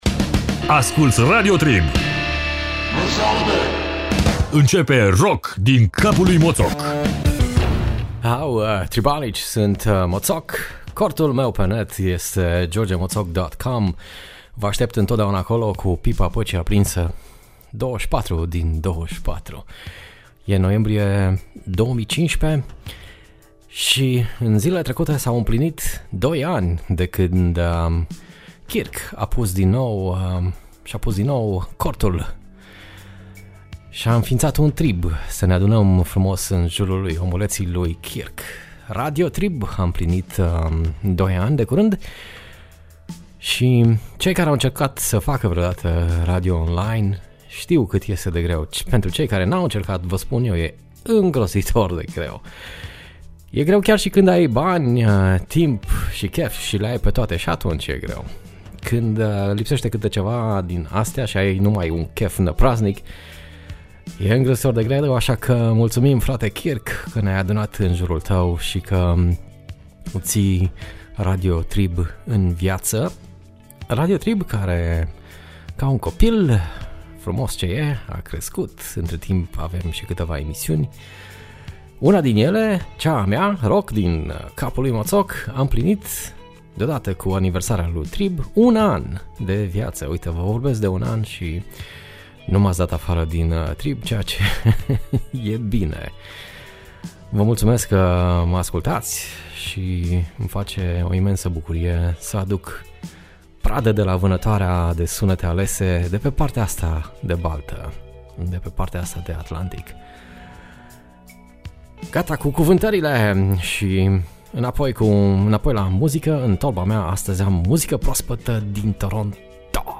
Emisiune difuzata pe Radio Trib.